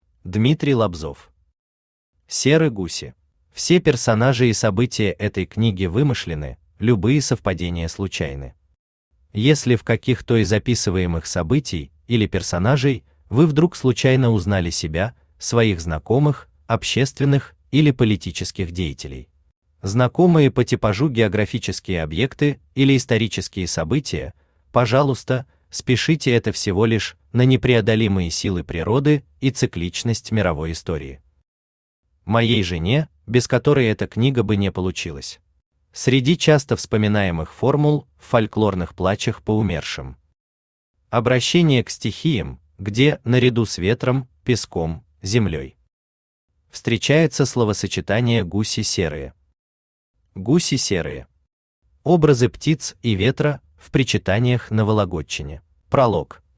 Аудиокнига Серы гуси | Библиотека аудиокниг
Aудиокнига Серы гуси Автор Дмитрий Лобзов Читает аудиокнигу Авточтец ЛитРес.